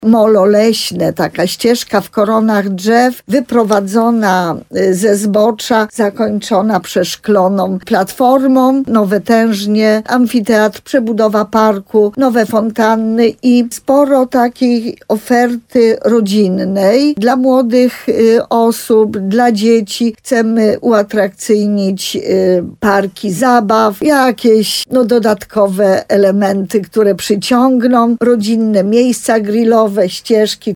Wójt gminy Sękowa Małgorzata Małuch mówi o atrakcjach, jakie mają docelowo zmienić centrum uzdrowiska.